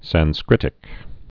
(săn-skrĭtĭk)